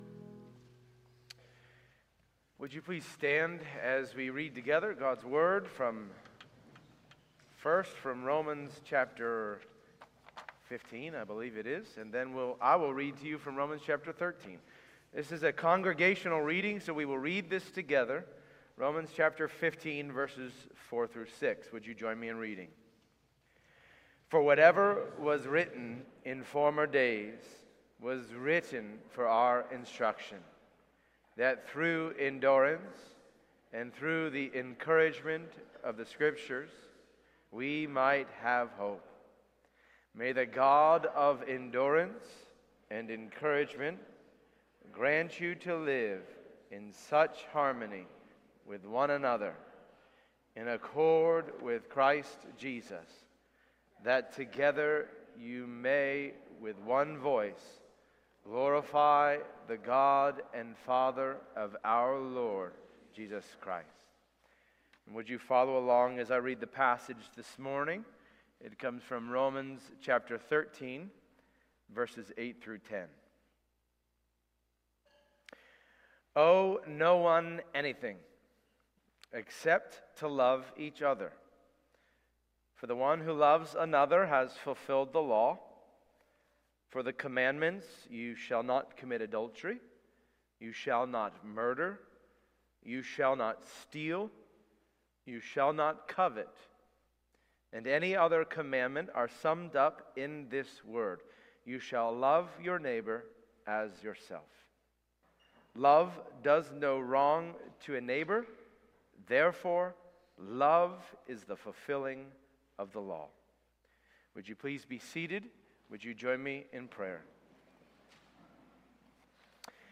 April-6-Worship-Service.mp3